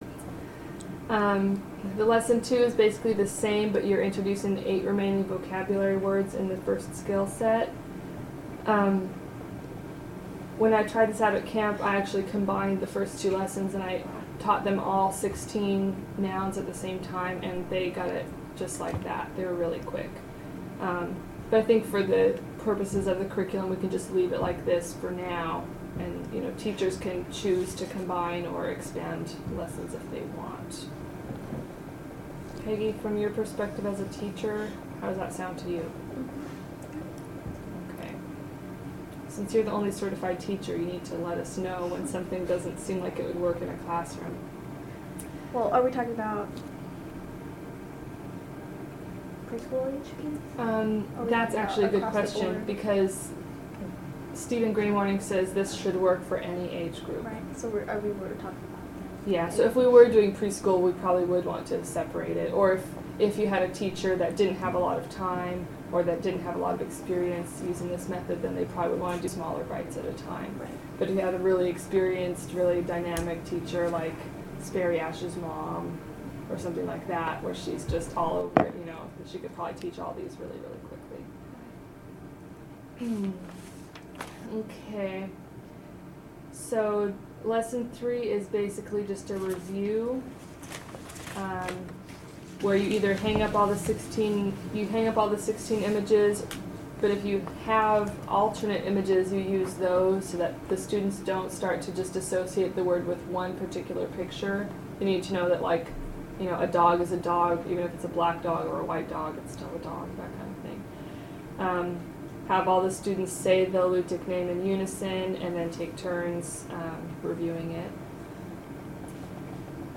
Curriculum Intensive Workshop | Alutiiq Museum Collections
class lessons
Kodiak, Alaska